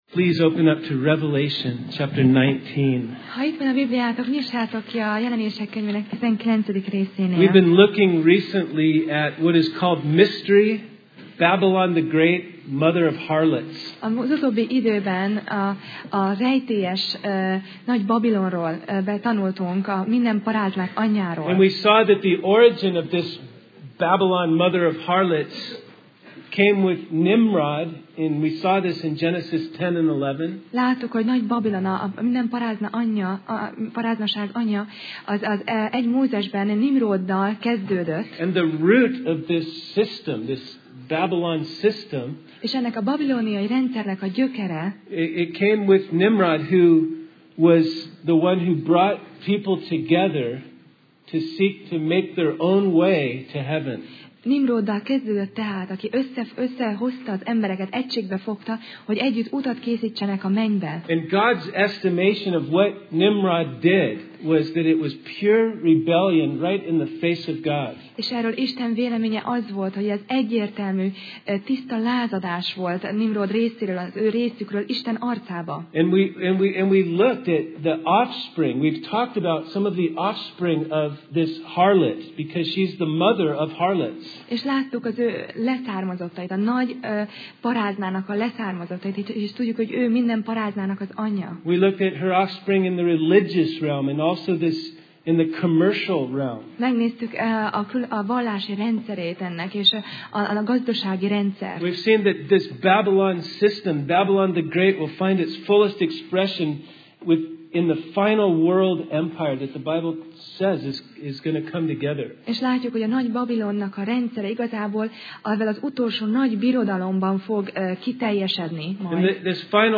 Passage: Jelenések (Revelation) 19:11-21 Alkalom: Vasárnap Reggel